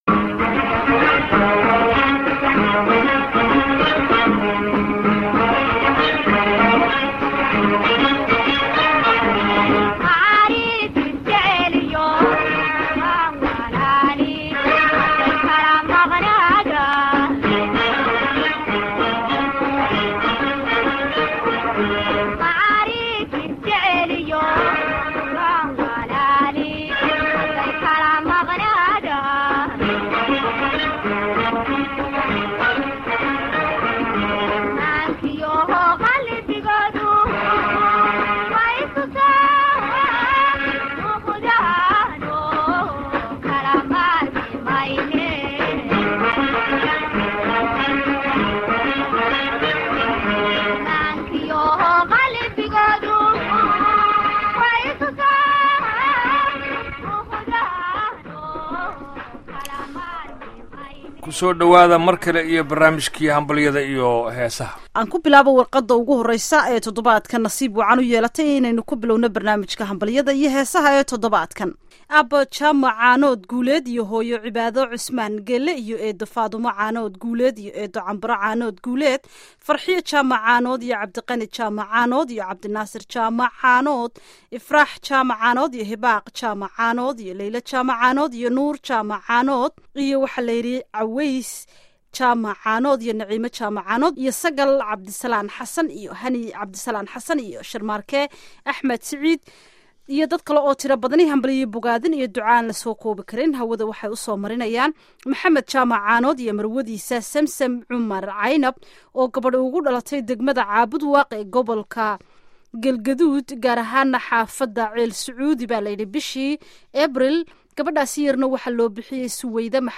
Barnaamijka Hambalyada iyo Heesaha waxaan idinkugu soo gudbinaa salaamaha ay asaxaabtu isu diraan, hadii ay noqon lahaayeen kuwa dhalashada ama aroosyada. Sidoo kale barnaamijka waxaad ku maqli doontaan heeso dabacsan.